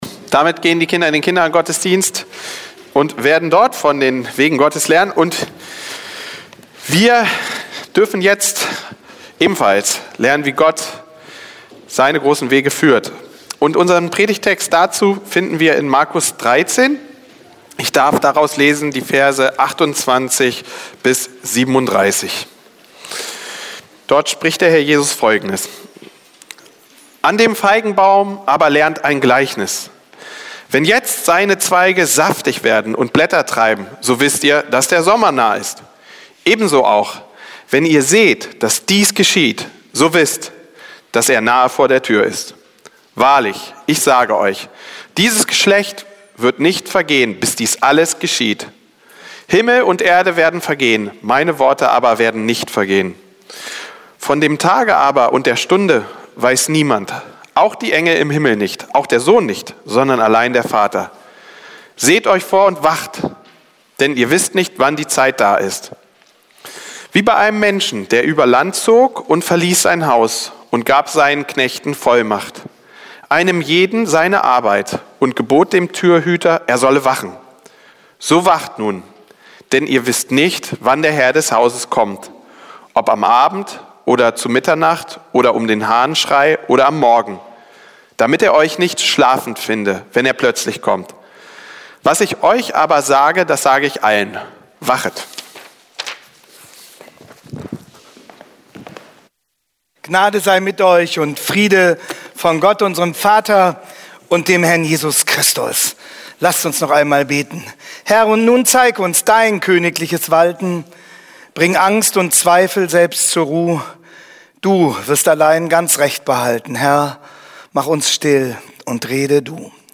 Predigt vom 22.03.2026 ~ BEG-Hannover Podcast